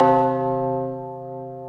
ORCH TUBU2-S.WAV